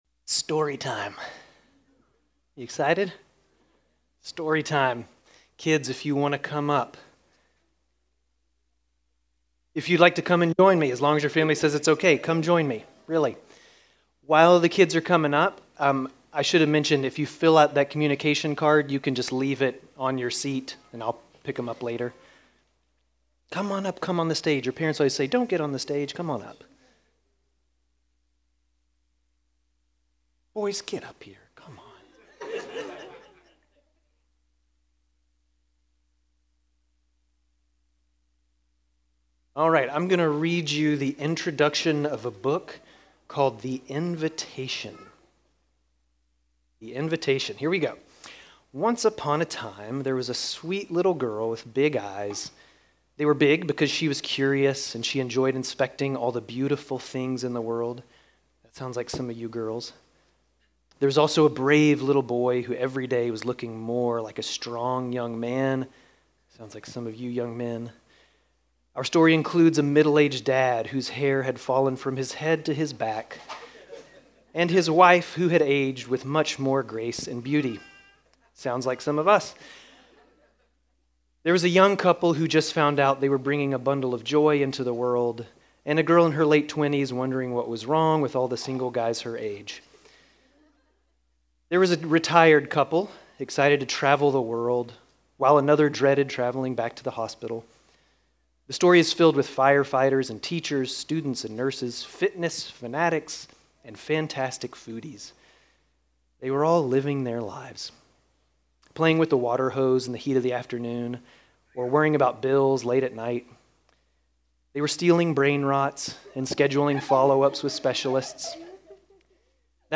Grace Community Church Dover Campus Sermons 8_31 Dover Campus Aug 31 2025 | 00:22:30 1x 00:00 / 00:22:30 Subscribe Share RSS Feed Share Link Embed